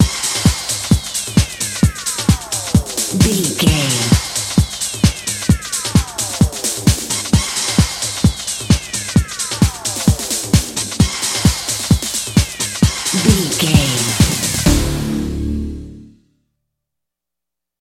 Aeolian/Minor
synthesiser
drum machine
90s
Eurodance